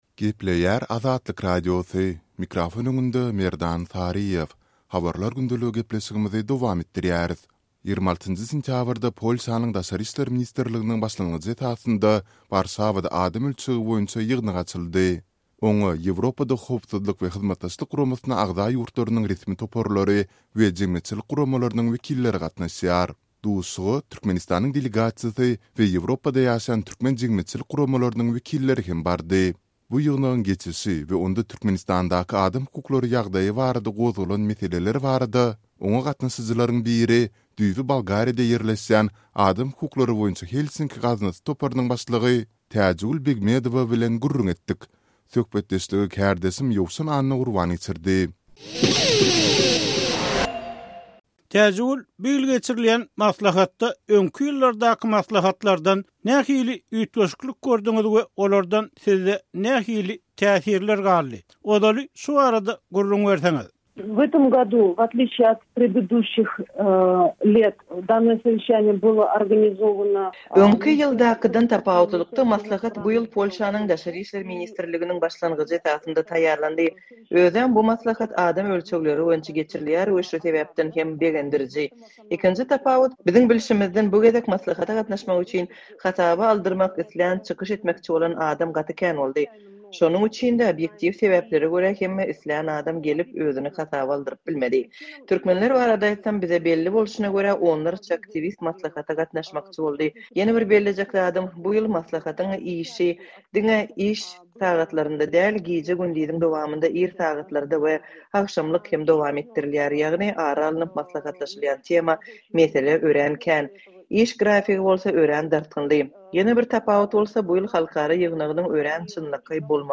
ABŞ-nyň Ýewropada Howpsuzlyk we Hyzmatdaşlyk Guramasyndaky hemişelik wekili Maýkl Karpenter 27-nji sentýabrda, Polşanyň paýtagty Warşawada adam ölçegleri boýunça geçirilen maslahatda eden çykyşynda, Türkmenistanda mart aýynda geçirilen prezident saýlawlarynyň ne azat, ne adalatly, ne-de bäsleşikli bolandygyny aýtdy we häkimiýetiň dinastiýa arkaly atadan ogla geçirilmegini tankyt etdi.